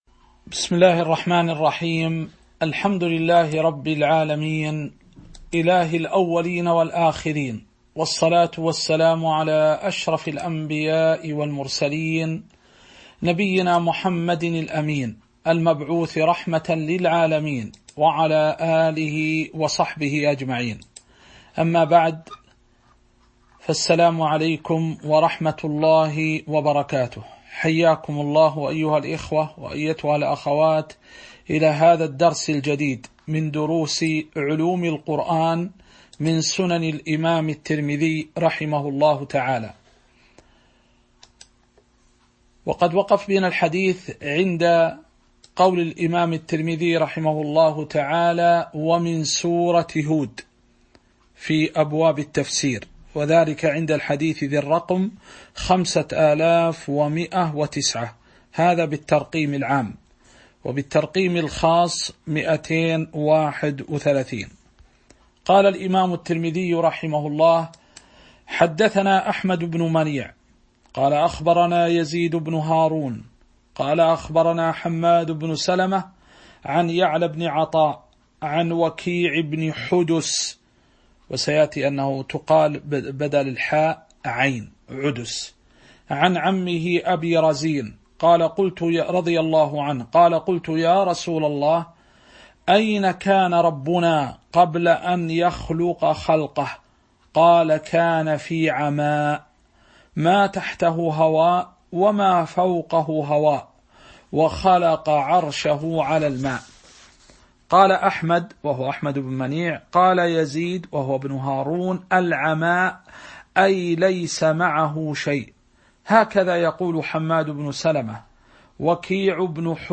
تاريخ النشر ٧ جمادى الأولى ١٤٤٣ هـ المكان: المسجد النبوي الشيخ